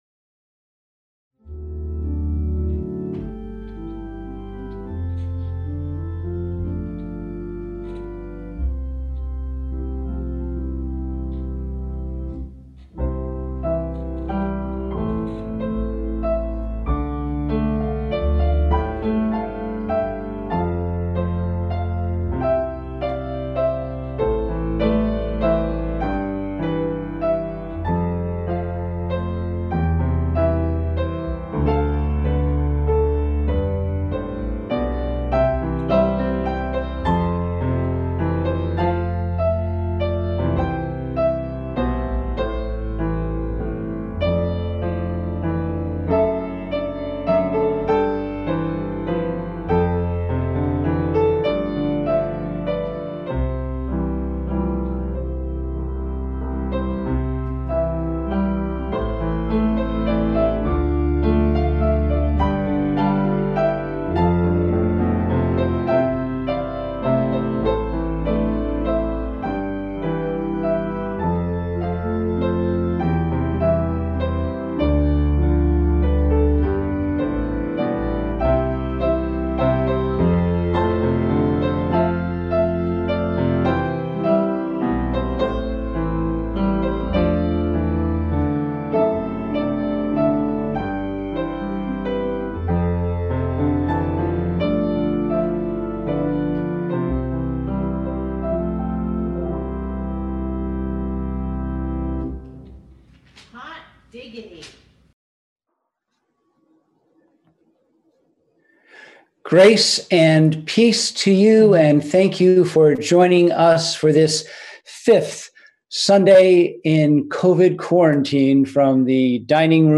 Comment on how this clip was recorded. We held virtual worship on Sunday, April 19, 2020 at 10am.